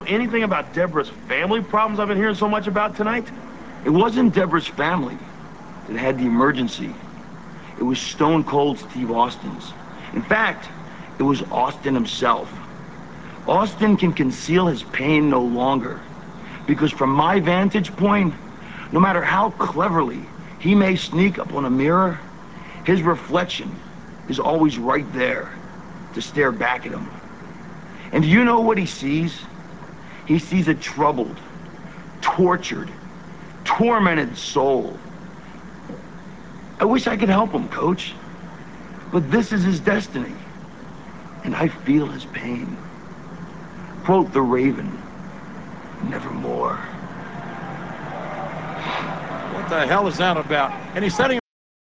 Jonathan Coachman finds Raven backstage and confronts him about Austin. Raven conveys what a tortured soul he sees in Austin and how he feels his pain, but this is Austin's destiny, quote the Raven, nevermore.